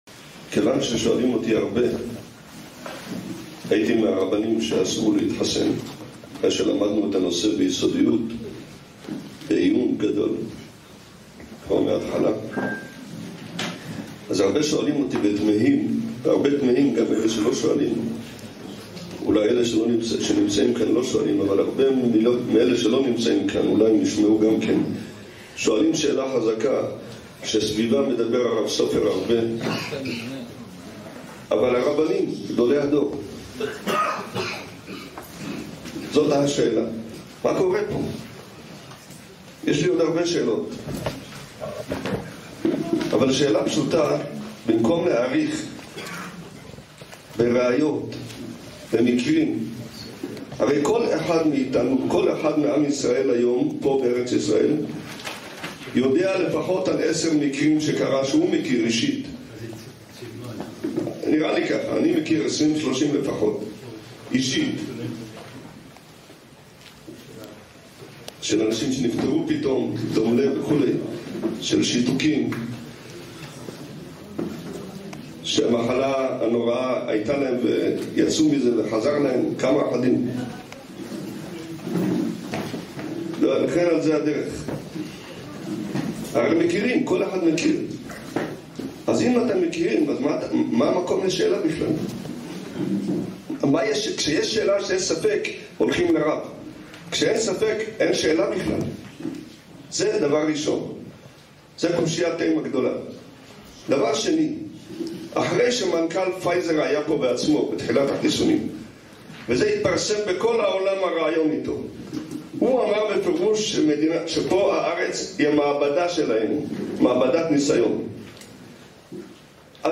בכנס אברכים